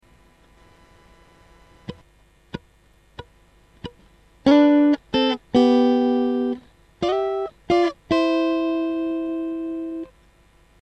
1 & 3 Strings 6ths Lick